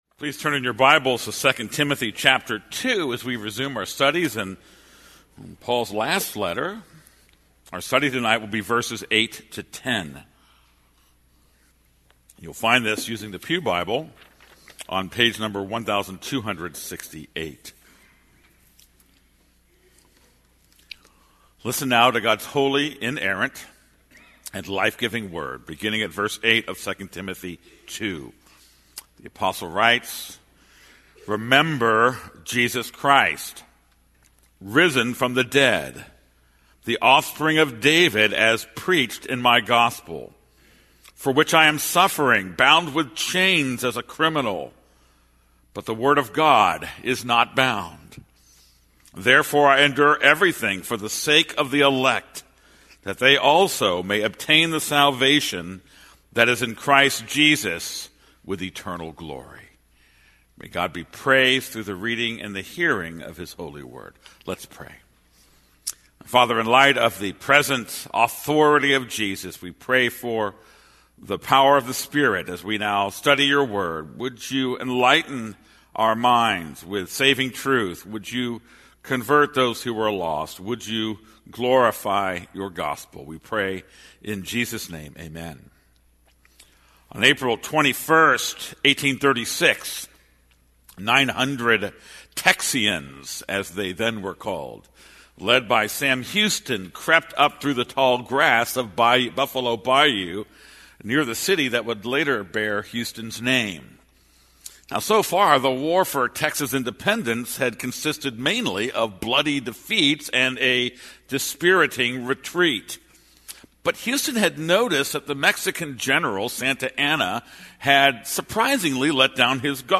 This is a sermon on 2 Timothy 2:8-10.